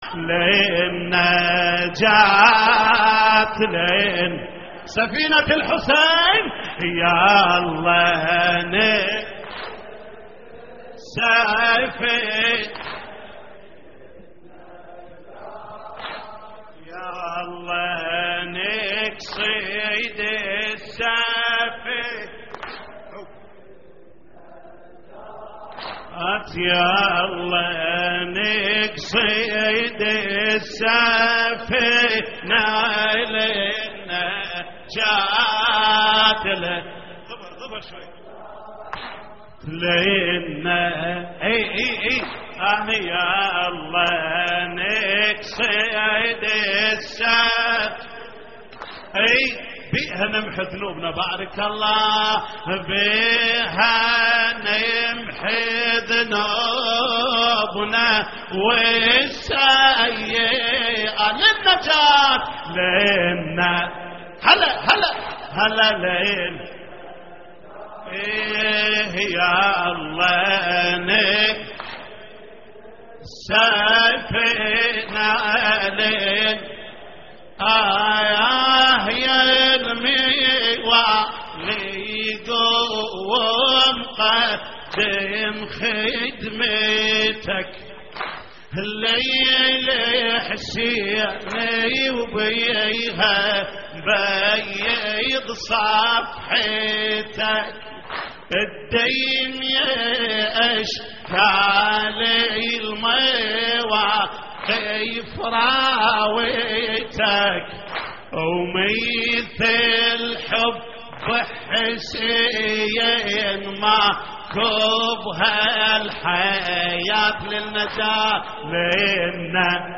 تحميل : للنجاة للنجاة يالله نقصد السفينة / الرادود جليل الكربلائي / اللطميات الحسينية / موقع يا حسين